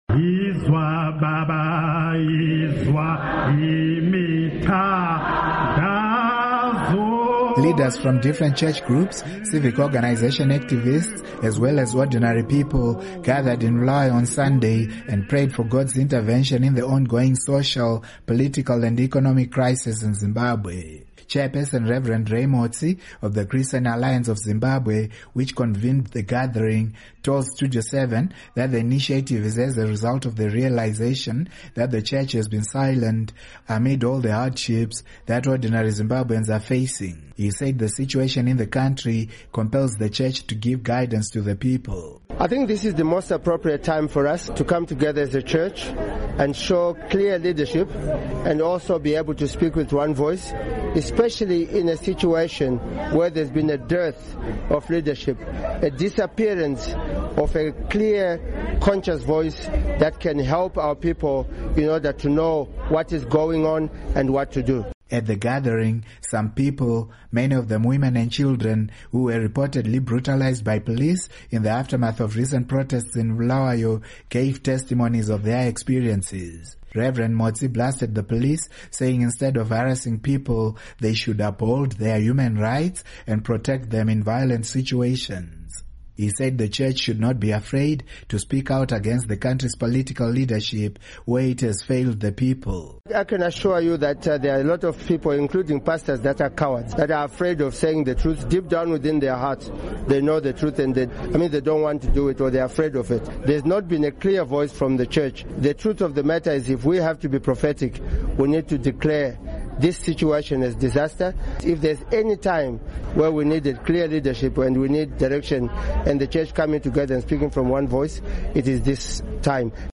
Report on Churches